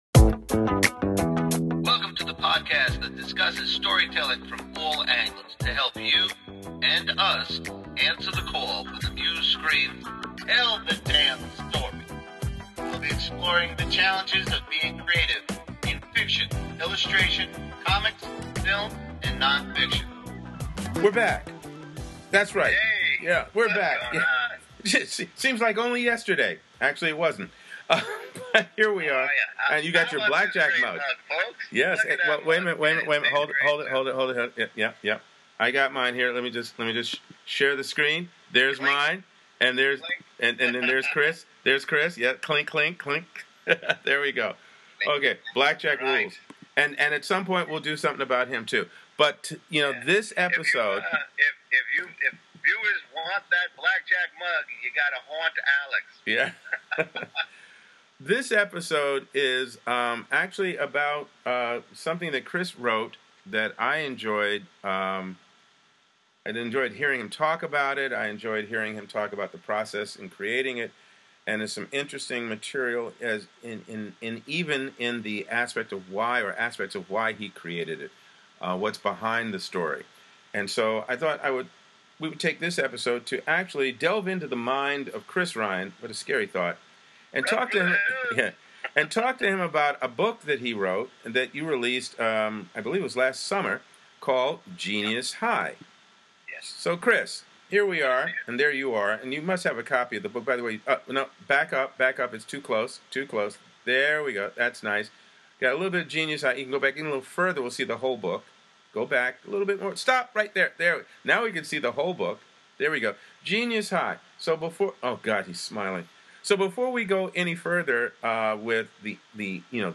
An interview with author